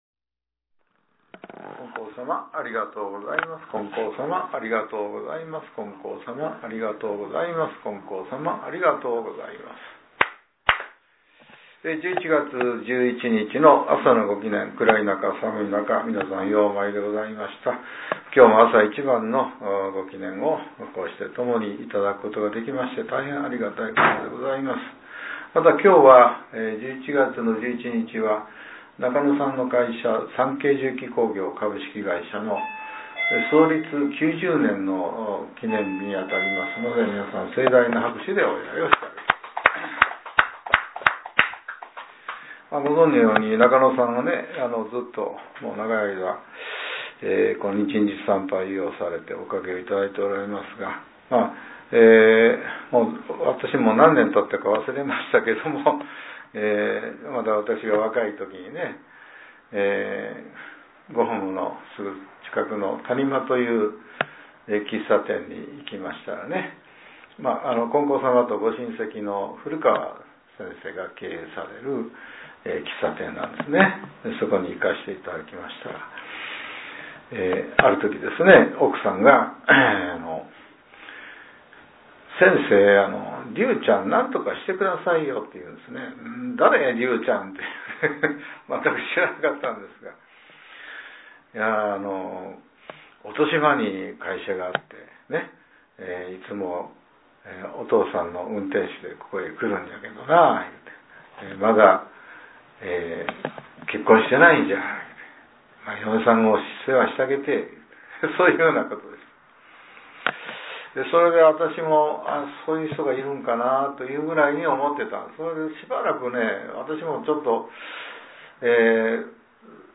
令和７年１１月１１日（朝）のお話が、音声ブログとして更新させれています。